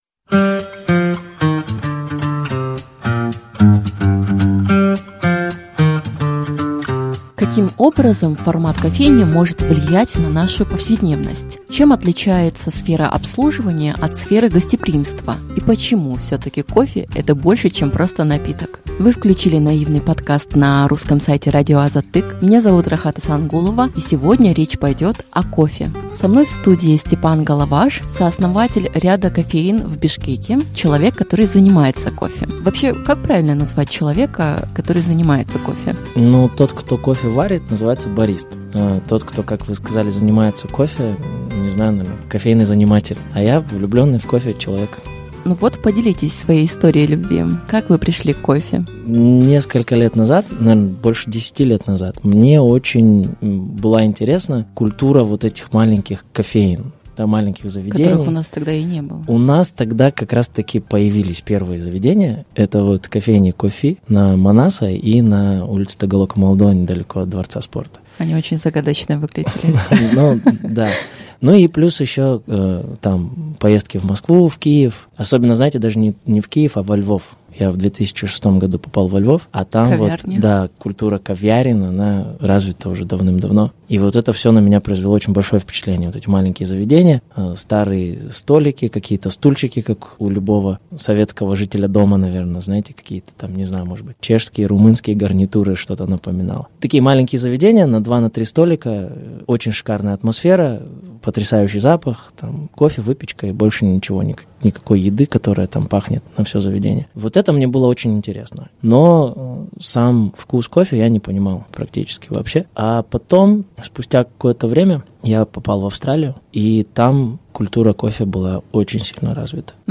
В этом выпуске «Наивного подкаста» речь пойдет о кофе. Гость студии